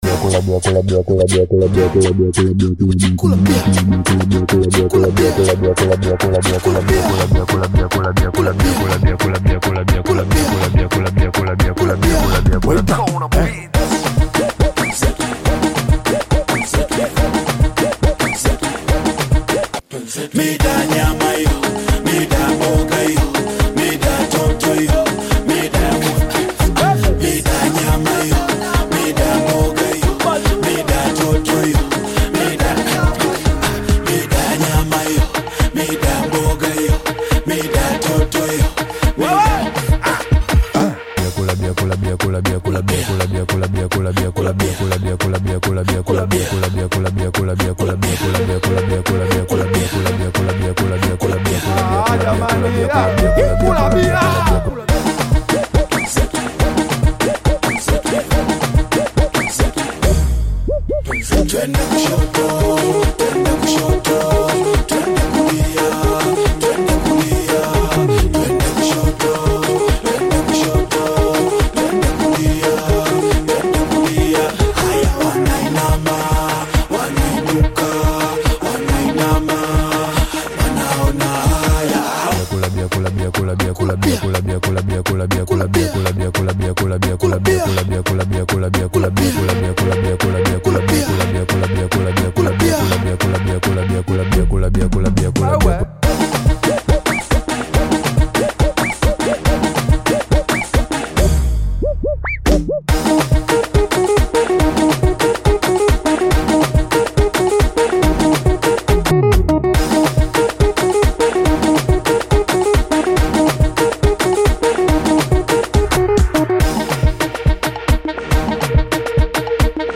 delivers a laid-back reflection on everyday life
blends smooth grooves with modern production